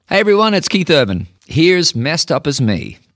LINER Keith Urban (Messed Up As Me) 2